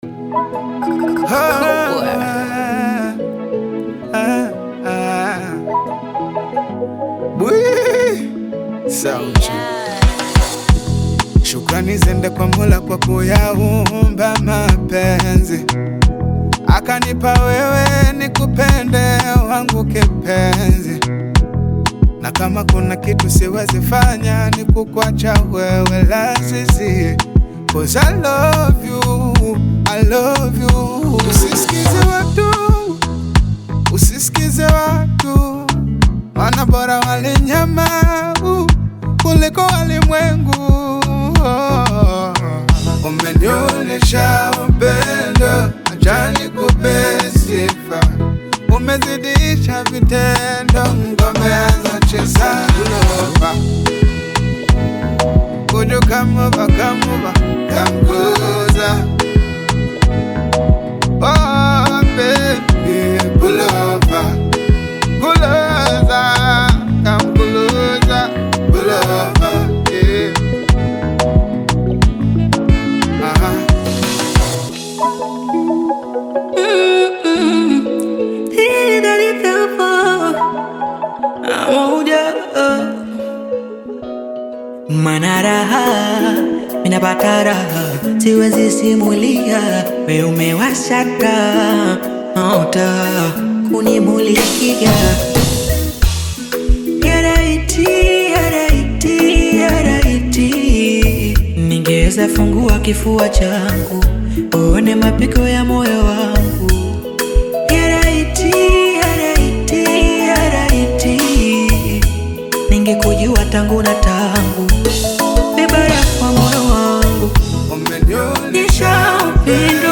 smooth Afro-Pop/Afrobeats single
Genre: Bongo Flava